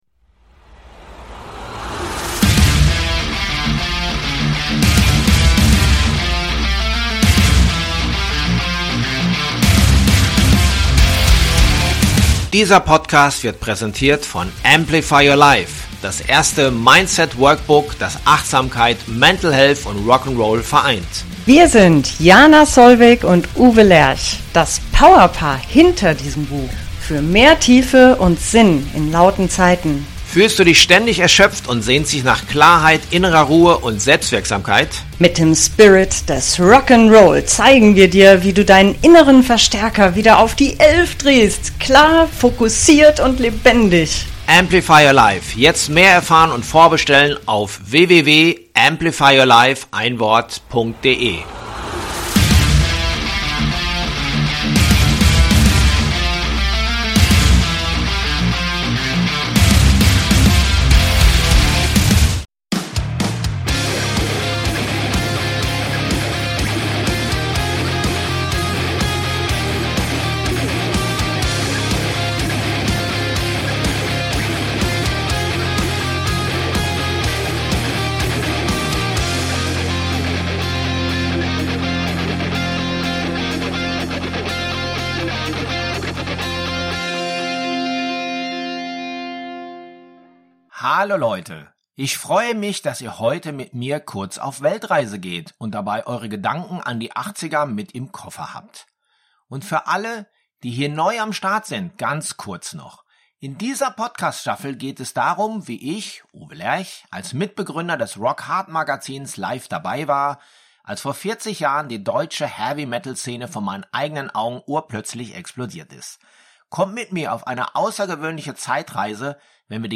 Wolf Hoffmann, Peter Baltes und Stefan Kaufmann über ihre Erinnerungen an diese wilde Zeit.